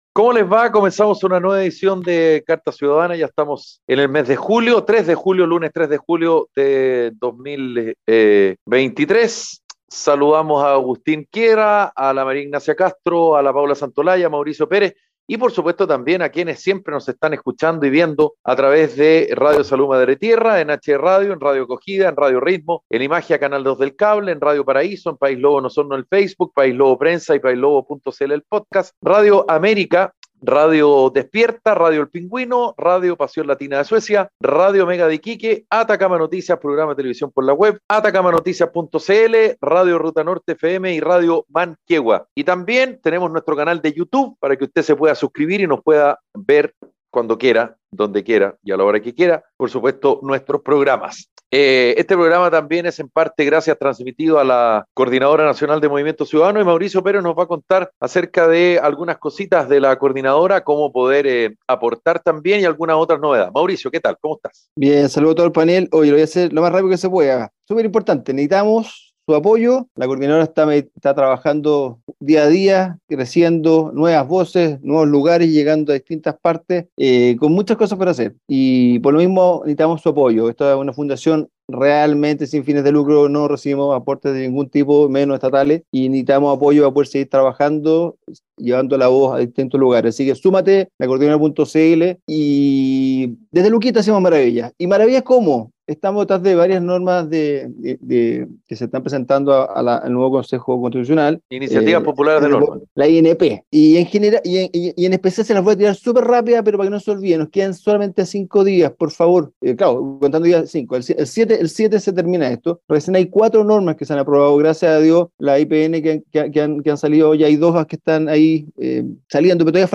Un programa radial de conversación y análisis sobre la actualidad nacional e internacional.